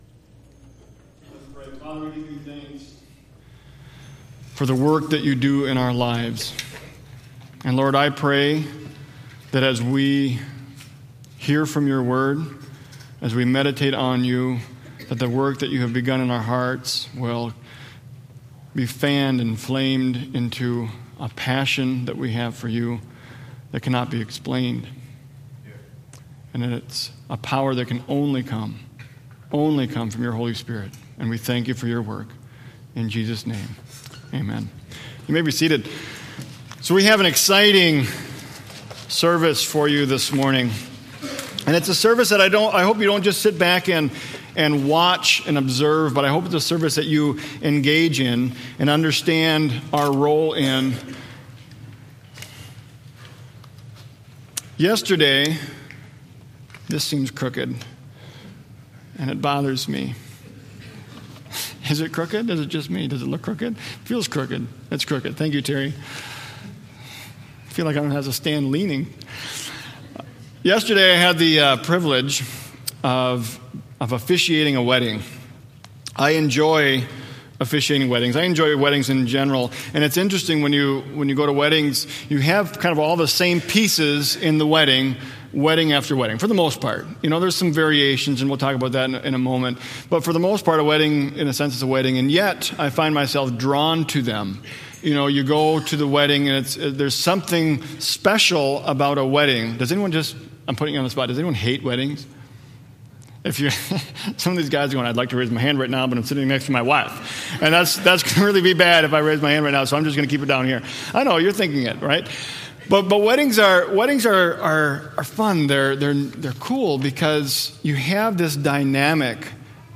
Watch our Sunday services online, download or listen to them here or through our podcast, and more